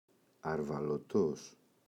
αρβαλωτός [arvalo’tos] – ΔΠΗ